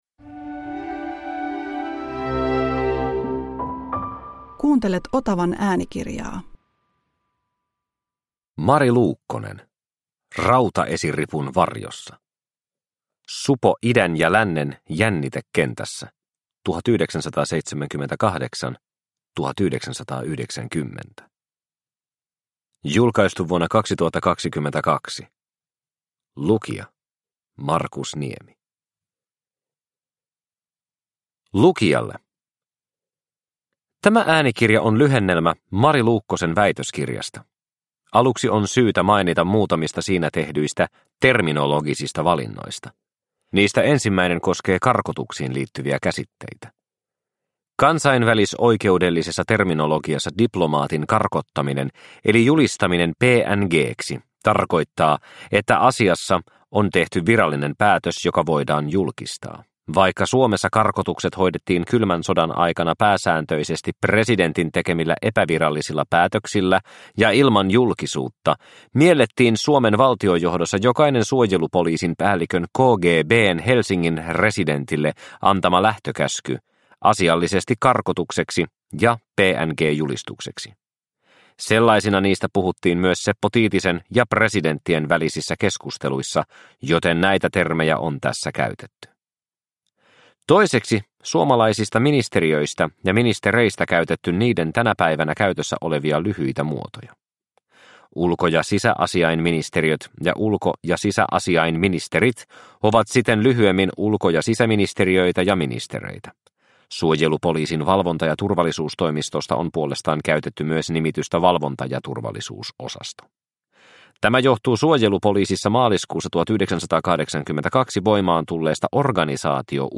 Rautaesiripun varjossa – Ljudbok – Laddas ner